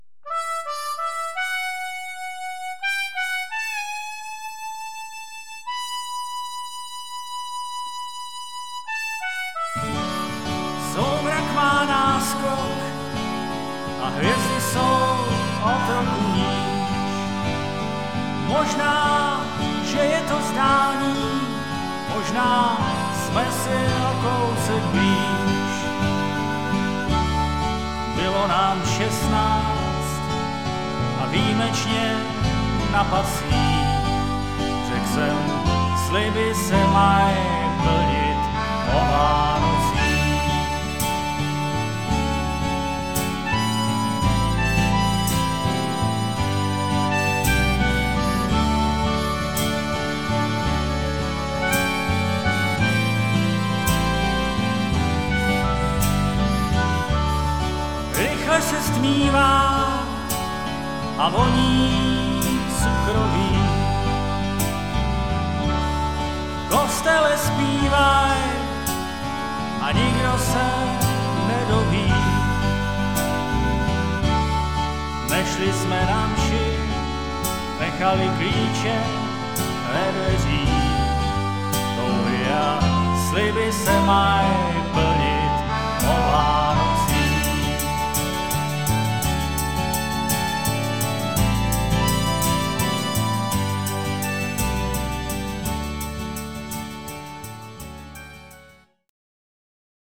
UKÁZKY SE ZPĚVÁKEM V TRIU